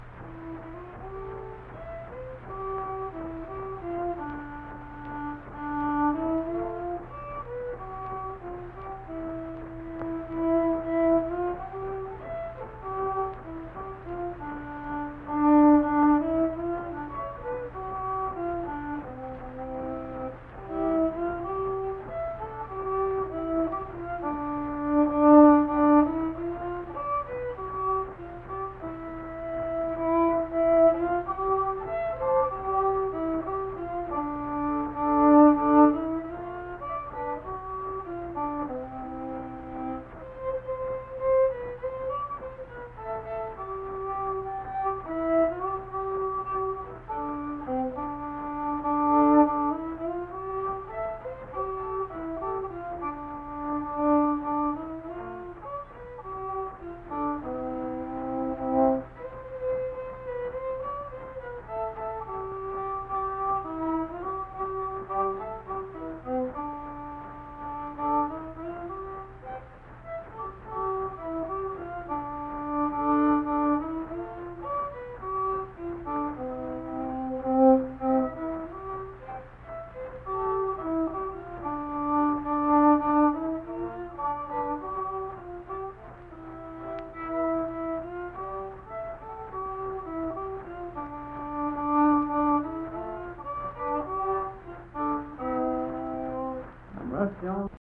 Unaccompanied fiddle music performance
Verse-refrain 2(1).
Instrumental Music
Fiddle